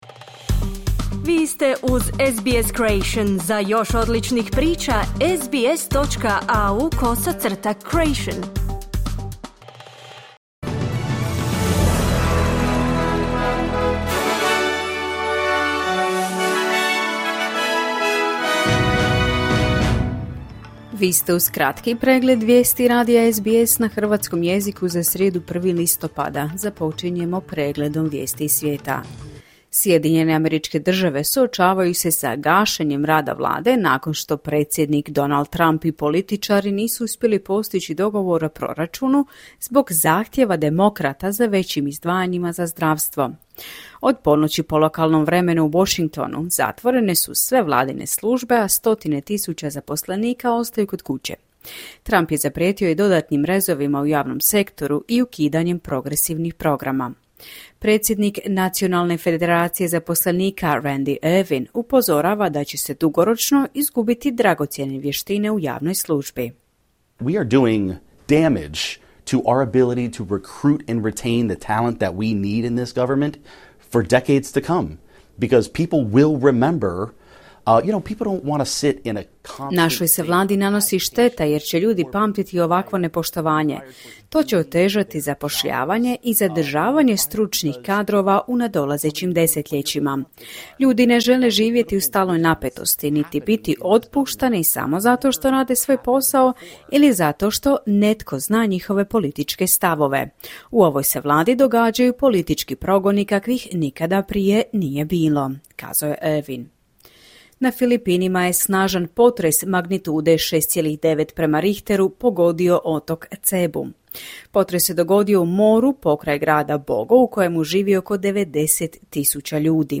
Vijesti radija SBS na hravstkom jeziku.